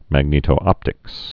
(măg-nētō-ŏptĭks)